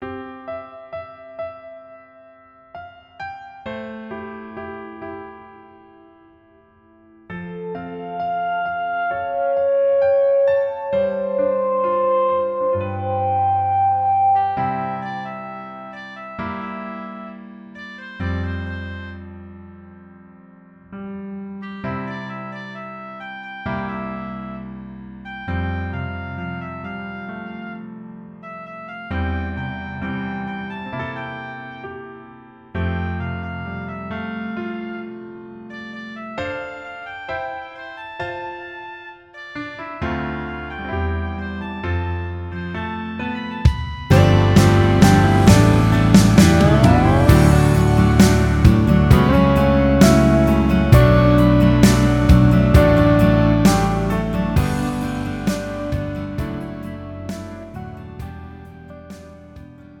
음정 -1키 3:40
장르 가요 구분 Pro MR